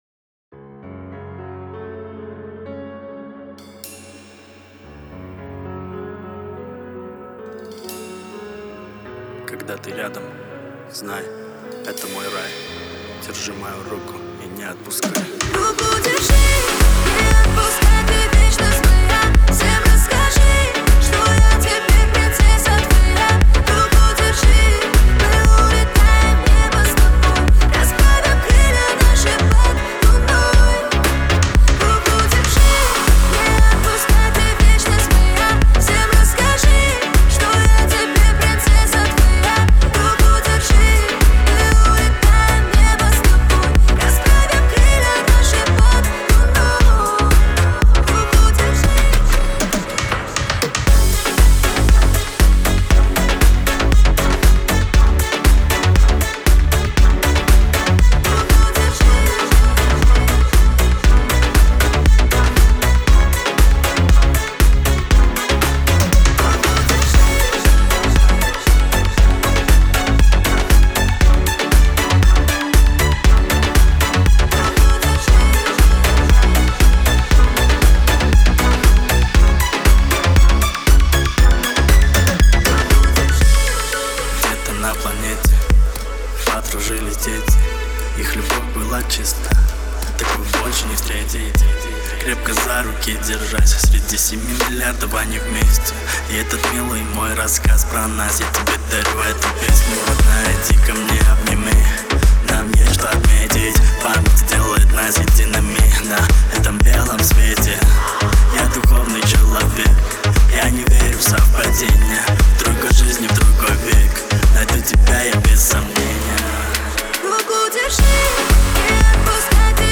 это энергичный трек в жанре электро-поп